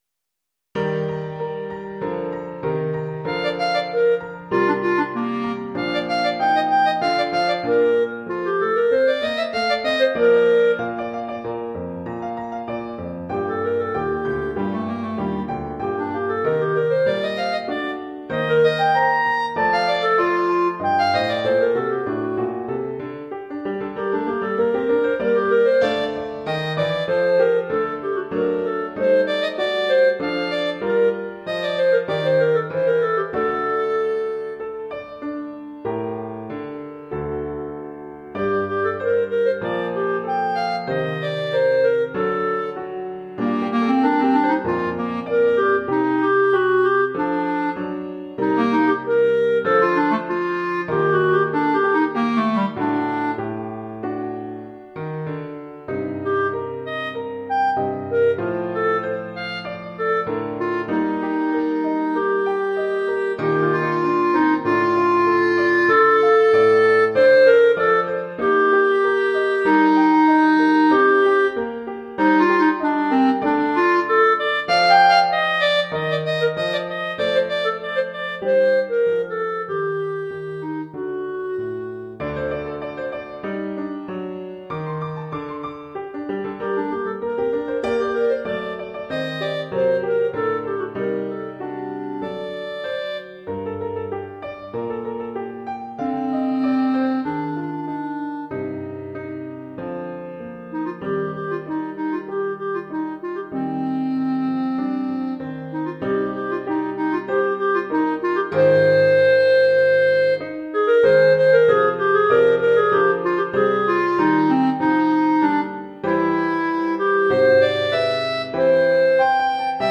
Genre musical : Classique
Formule instrumentale : Clarinette et piano
Oeuvre pour clarinette et piano.